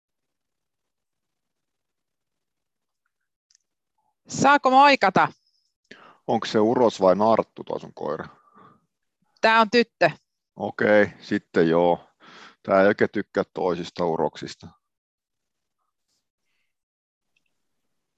dialogi-1.mp3